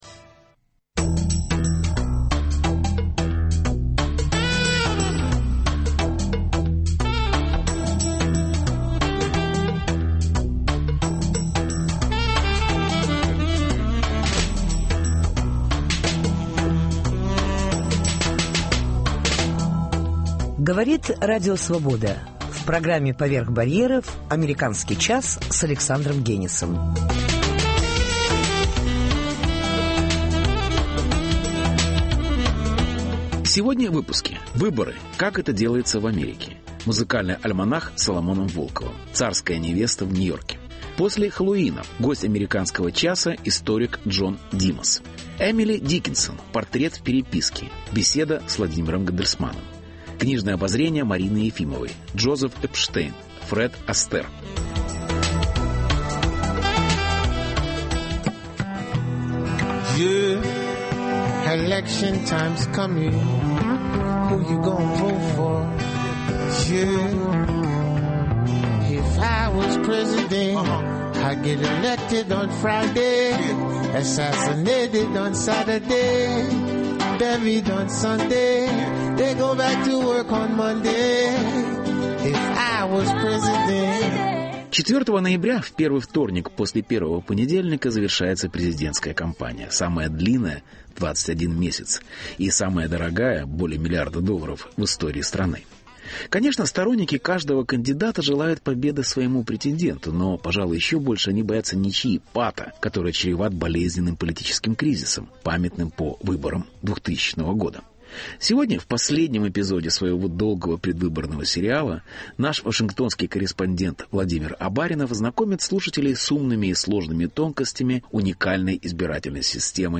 Репортаж. Выборы: как это делается в Америке.